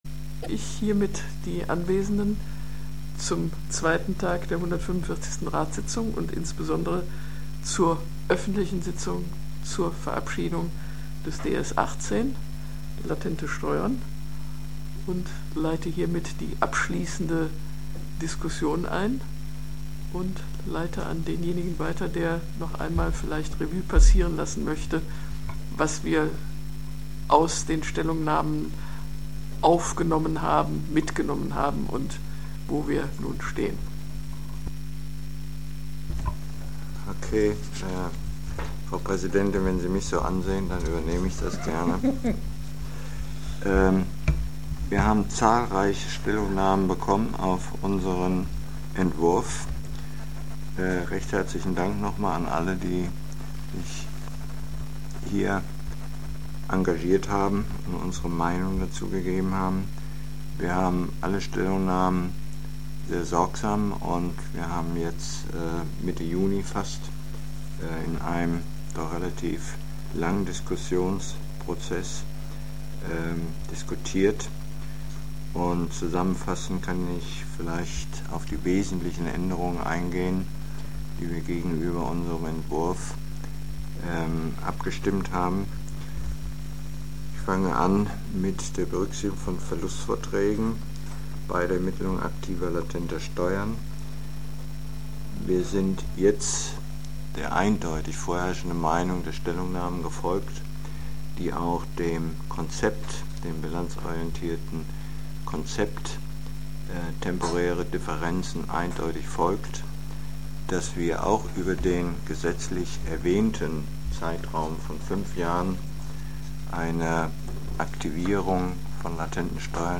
145. DSR-Sitzung und 16. Öffentliche Sitzung des DSR • DRSC Website